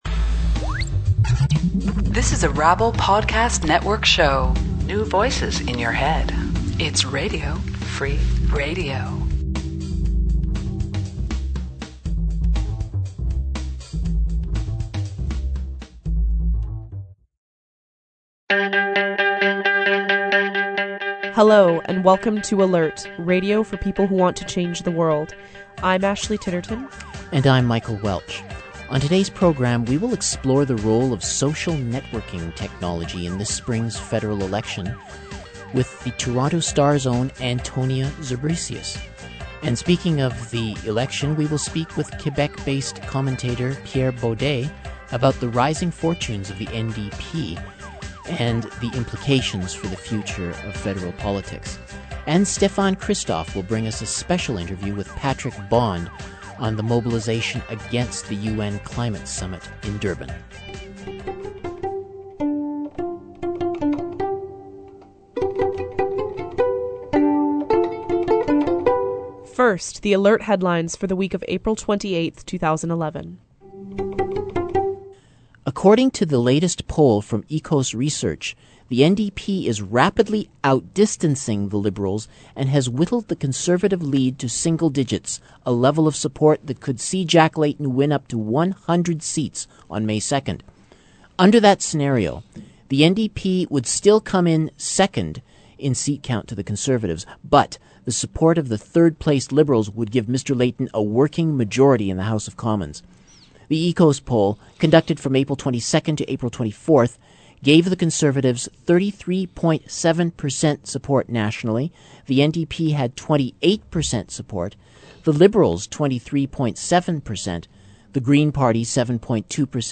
Genre: Current Affairs